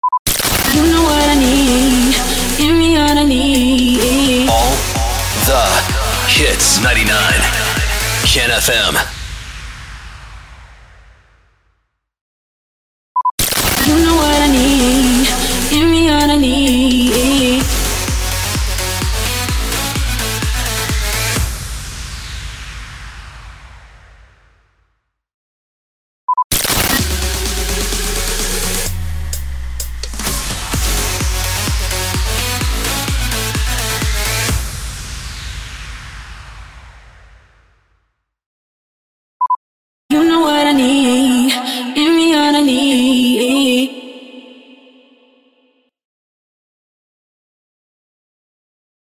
762 – SWEEPER – GIVE ME ALL I NEED
762-SWEEPER-GIVE-ME-ALL-I-NEED.mp3